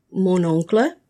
When one word ends in an “n” or “s” and is followed by a word that starts with a vowel sound, you must pronounce the “n” as itself and the “s” as a “z”.
Click on each of the following examples of liaison, and repeat the proper pronunciation after the speaker.